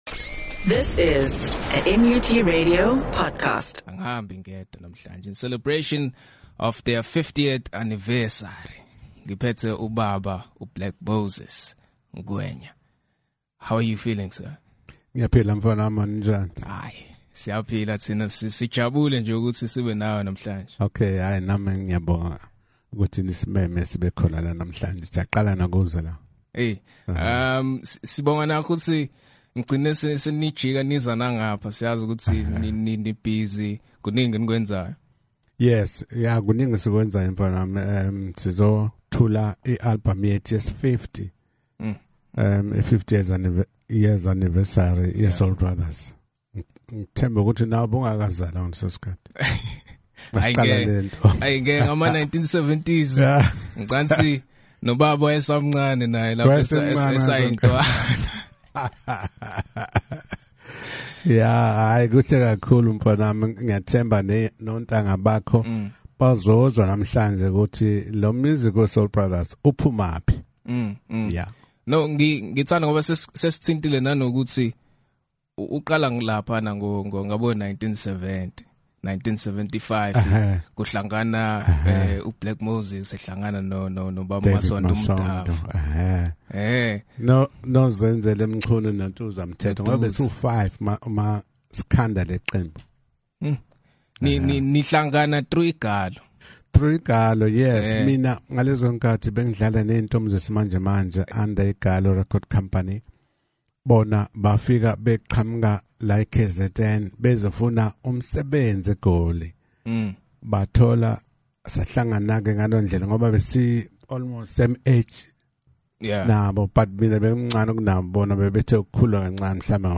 In this special anniversary interview, legendary organ maestro Moses “Black Moses” Ngwenya reflects on 50 years of Soul Brothers — from the group’s humble beginnings to their rise as icons of uMbhaqanga. He speaks passionately about preserving the genre’s heritage while embracing modern music technology, sharing insights into the innovative production approach behind their upcoming project Thath’owakho, set for release on 28 November 2025.